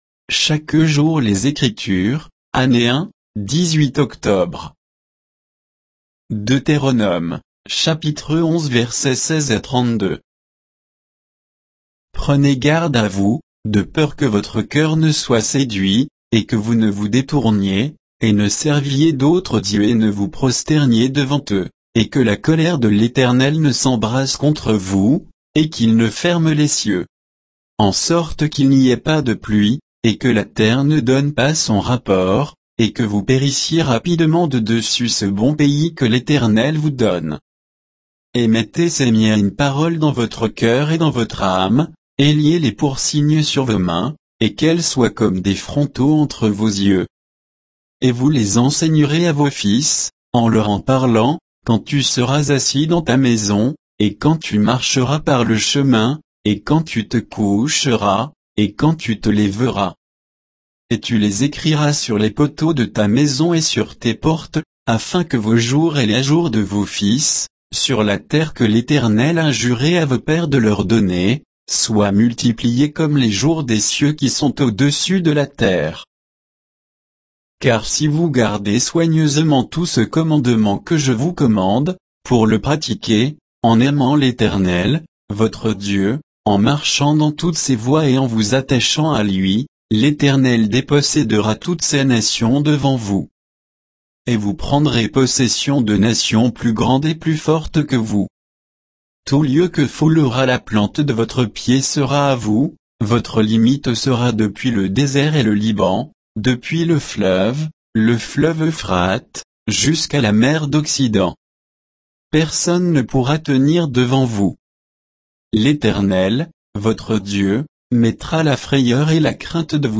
Méditation quoditienne de Chaque jour les Écritures sur Deutéronome 11